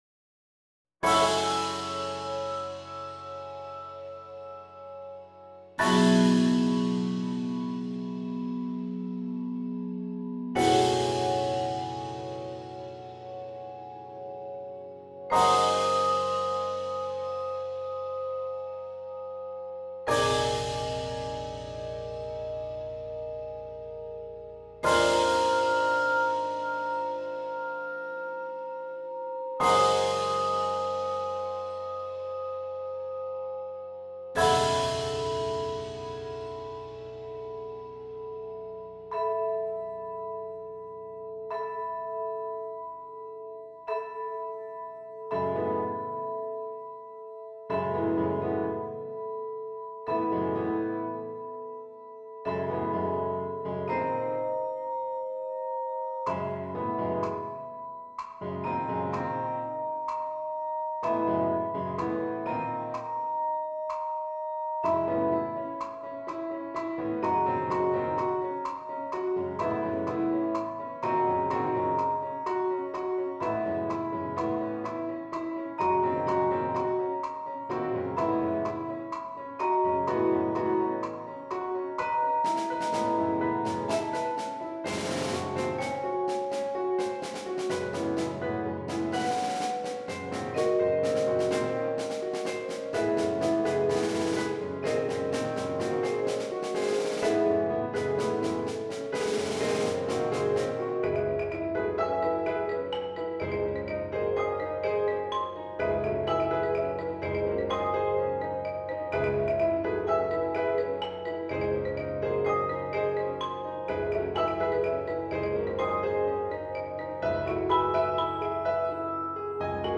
PDF score MIDI recording for reference